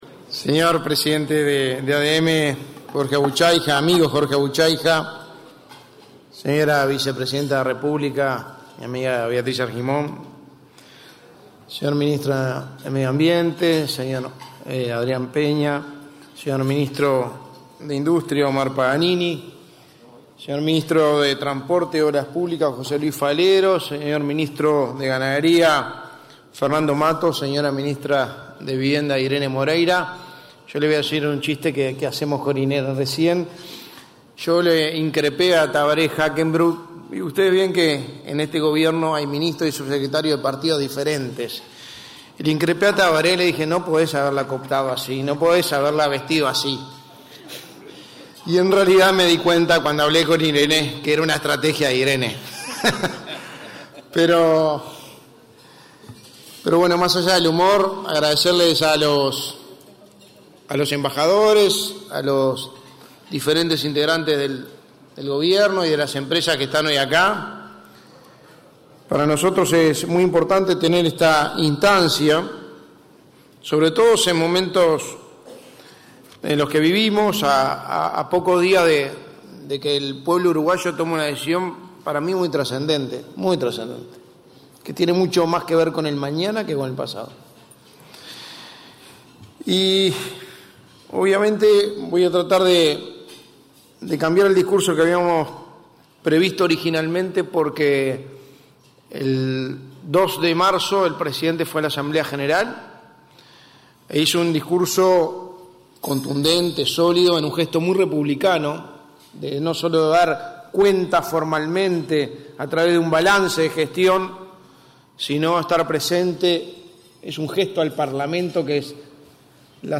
Palabras del secretario de Presidencia de la República, Álvaro Delgado
Palabras del secretario de Presidencia de la República, Álvaro Delgado 09/03/2022 Compartir Facebook X Copiar enlace WhatsApp LinkedIn El secretario de Presidencia, Álvaro Delgado, disertó, este 9 de marzo, en el almuerzo de trabajo organizado por la Asociación de Dirigentes de Marketing del Uruguay.